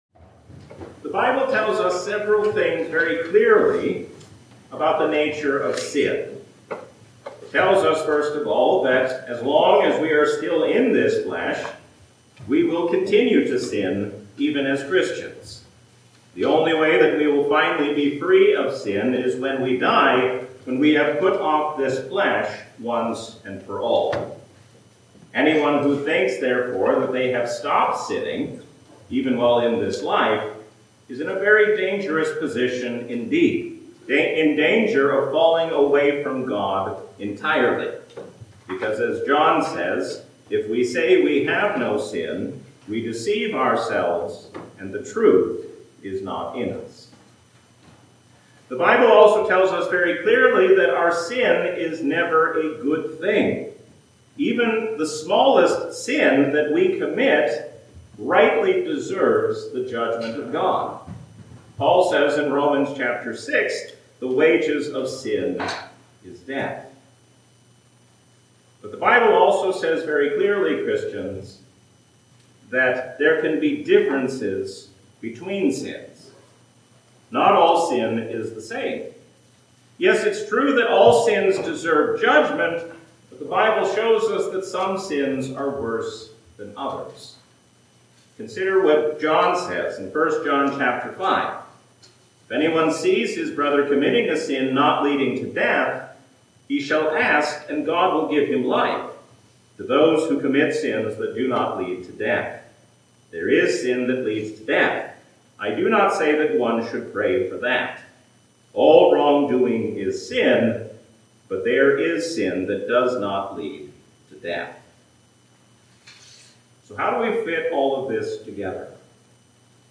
A sermon from the season "Epiphany 2025." Stephen shows us what it means to be like Jesus even in a difficult hour.